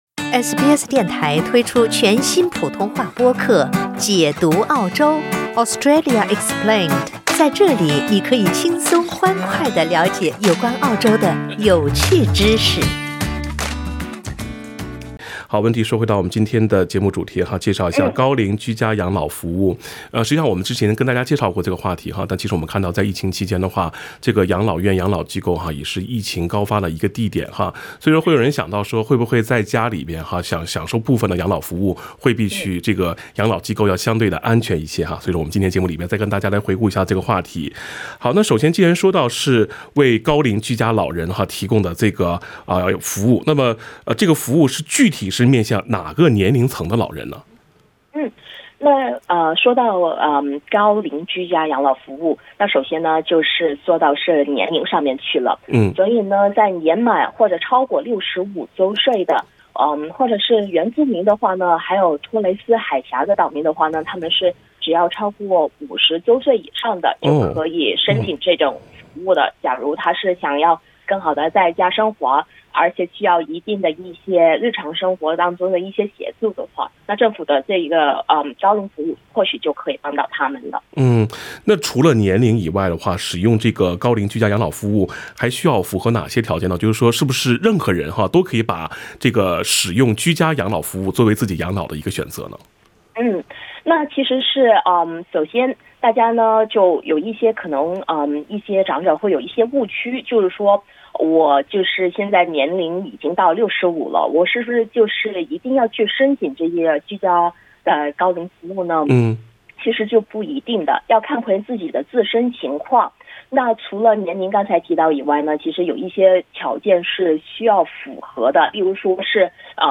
SBS Chinese 17:11 cmn 《澳洲福利知道多少》听众热线逢每月第四个周一上午8点20分至9点播出。